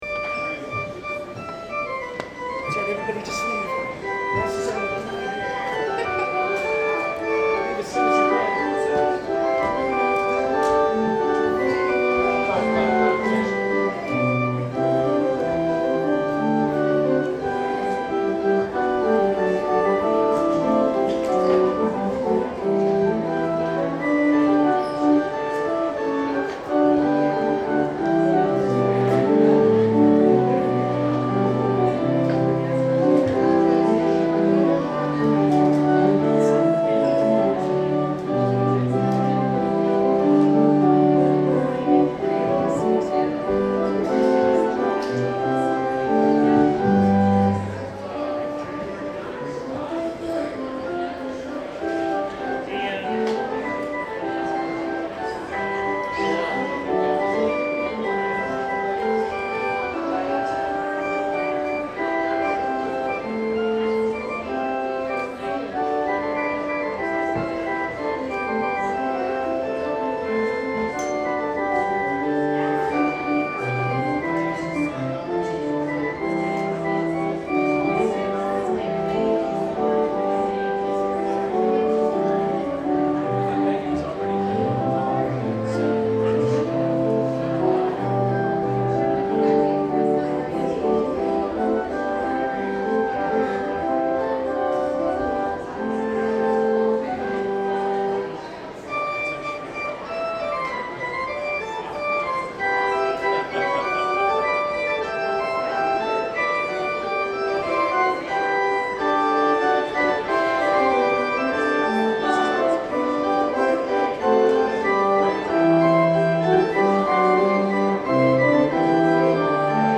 Postlude 2017-01-29
Organ Solo